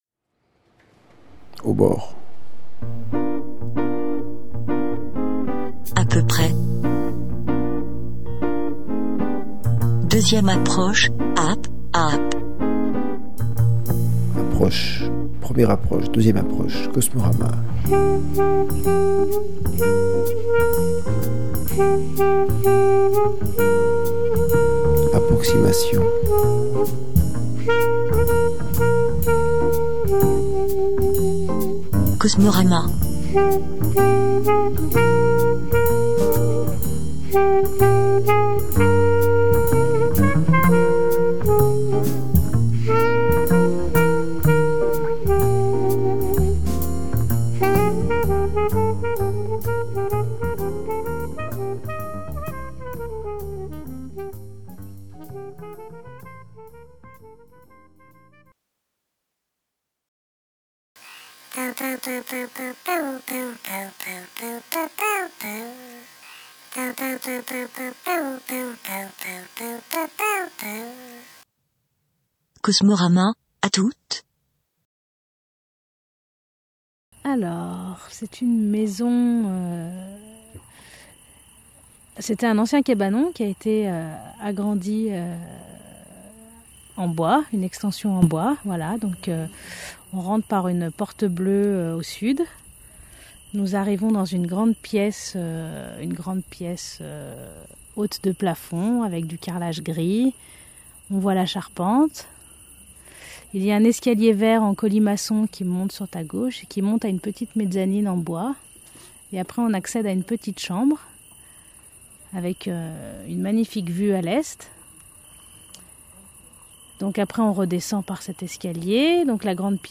Télécharger en MP3 rec / tente carillon ; tsf ; amoureuse mus equip / Familia Miranda Laisser un commentaire Laisser un commentaire Annuler la réponse Votre adresse e-mail ne sera pas publiée.